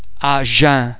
un um vowel base similar to ir in [sir]
The French [un] nasal vowel sound is made up of the [ oe ] vowel base which is subsequently nasalised by the air being passed through the mouth and the nostrils at the same time.